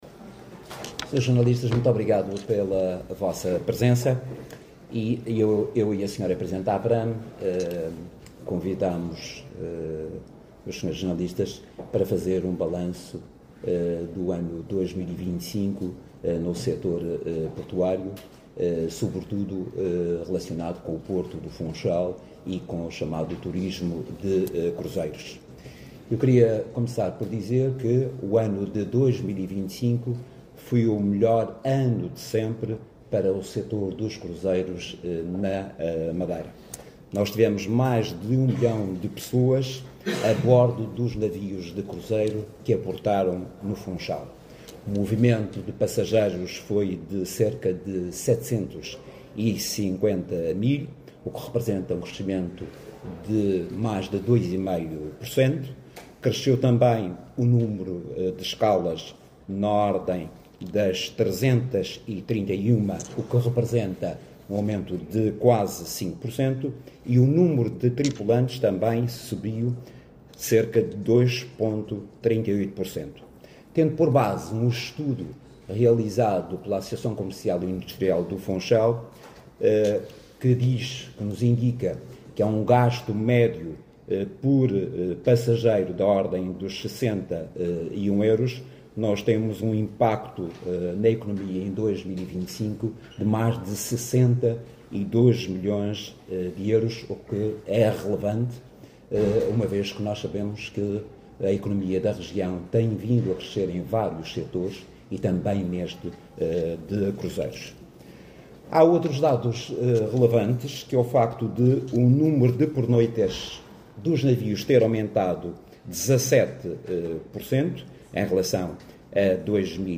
Conferência de Imprensa (SOM)